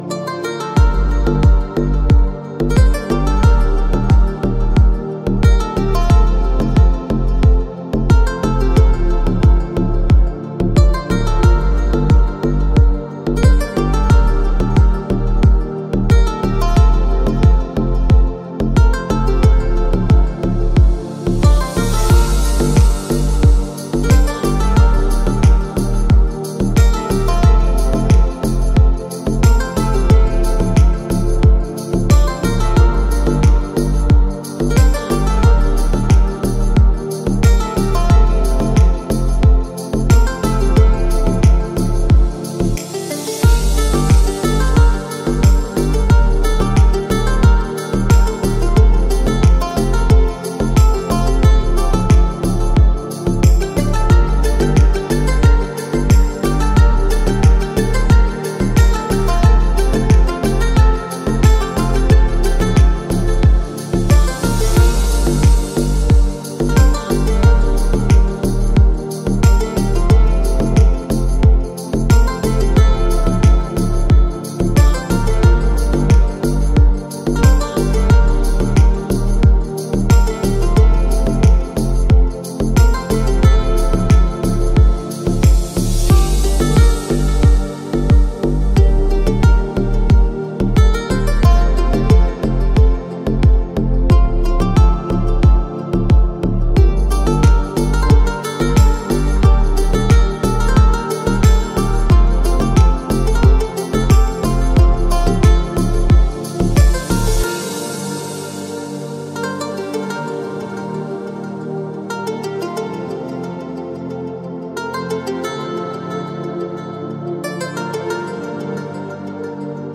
Спокойная музыка
расслабляющая музыка
спокойные треки